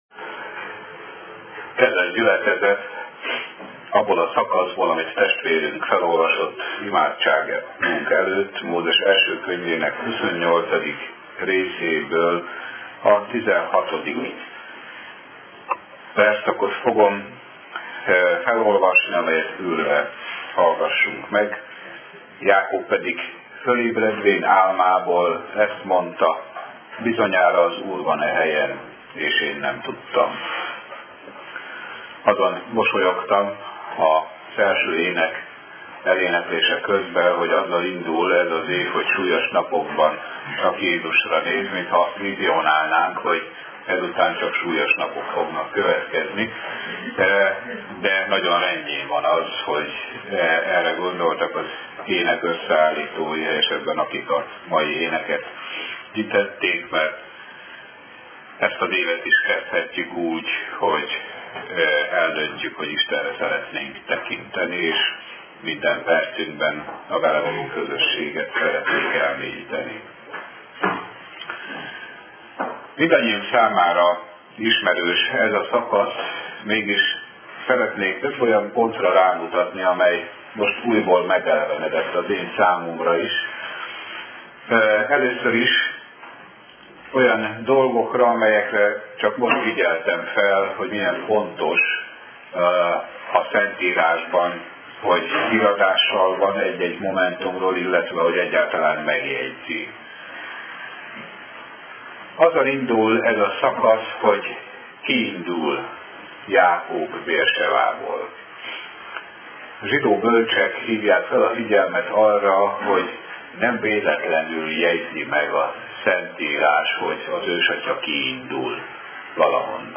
2013. évi igehirdetések | Méltóság Napja Templom